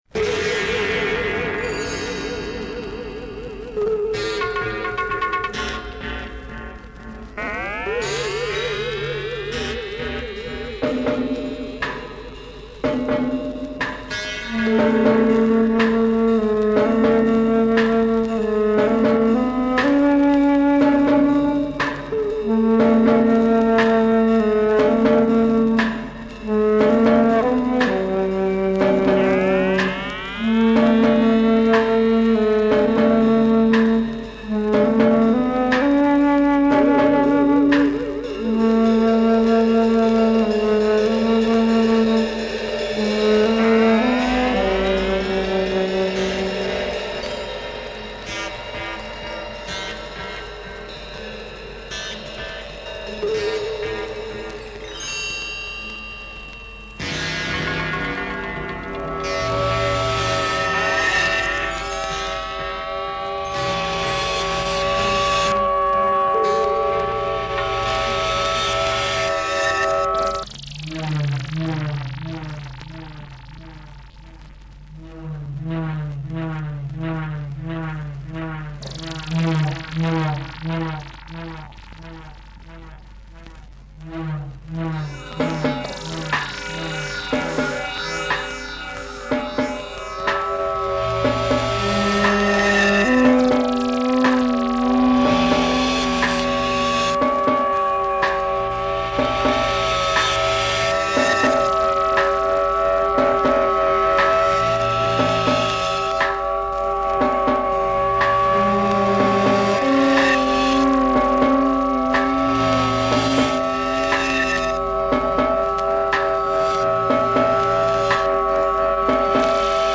instrumental track
oriental / southern russia sounds